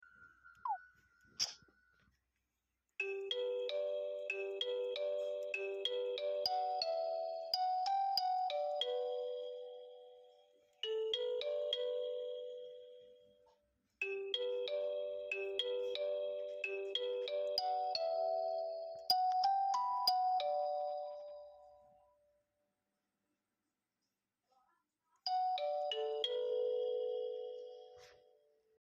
(The clip might have some minor noise)